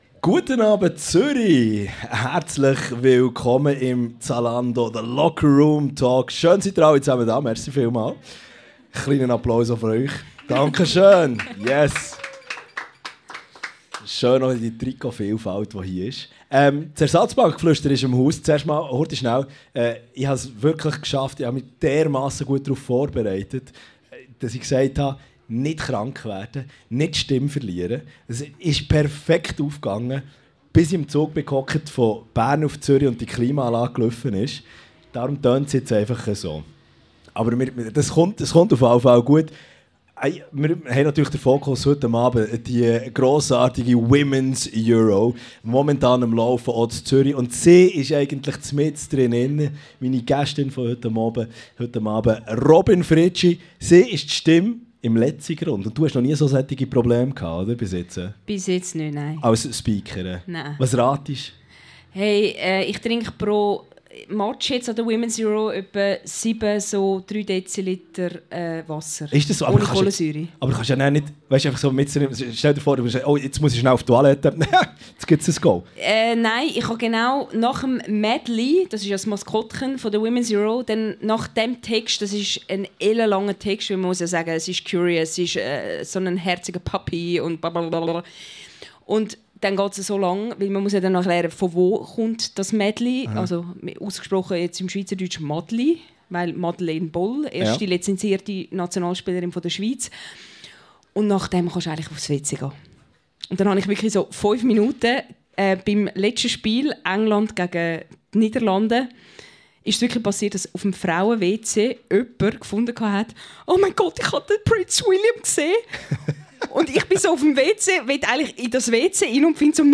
Sommerliche Grüsse mit der Live-Folge des Locker Room Talks by Zalando aus dem Charlatan in Zürich vom Freitag 11.07.2025.